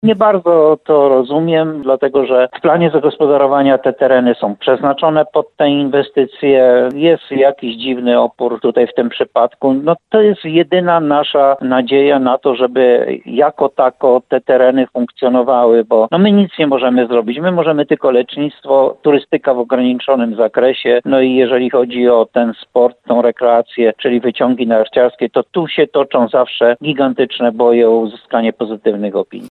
To powoduje, że szanse uruchomienia wyciągów w najbliższym sezonie są nikłe – mówił Jan Golba w programie Słowo za Słowo w radiu RDN Nowy Sącz.